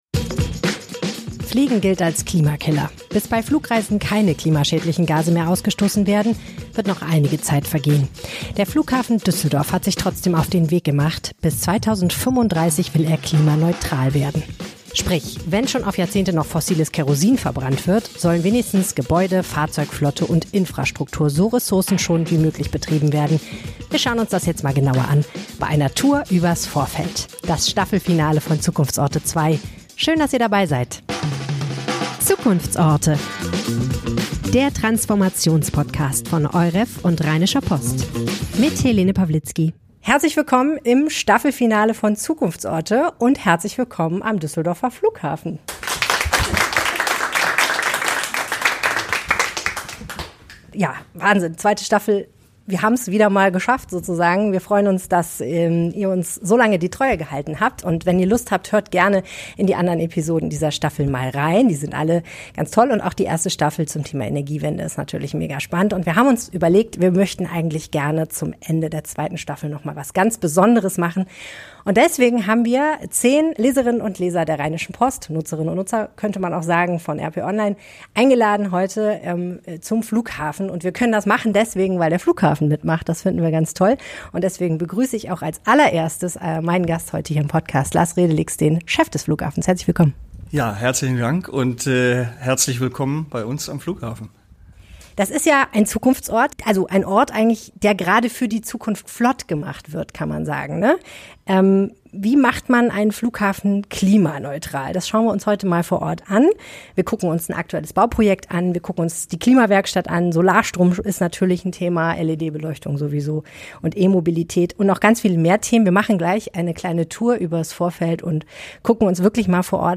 Der Flughafen Düsseldorf will keiner sein. Eine Tour übers Vorfeld.